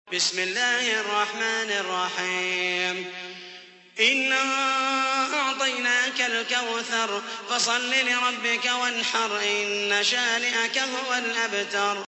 تحميل : 108. سورة الكوثر / القارئ محمد المحيسني / القرآن الكريم / موقع يا حسين